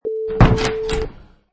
Royalty free sound: Door Shuts 1
Door shuts and door knob turns into position
Product Info: 48k 24bit Stereo
Category: Doors / Doors General
Relevant for: shuts, slam, shut.
Try preview above (pink tone added for copyright).
Door_Shuts_1.mp3